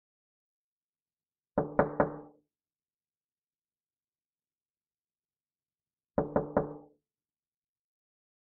دانلود صدای در زدن 3 از ساعد نیوز با لینک مستقیم و کیفیت بالا
جلوه های صوتی
برچسب: دانلود آهنگ های افکت صوتی اشیاء دانلود آلبوم صدای در زدن – انواع مختلف از افکت صوتی اشیاء